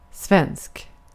Uttal
Synonymer svenskspråkig Uttal : IPA: [svɛnsk] Ordet hittades på dessa språk: svenska Översättning 1.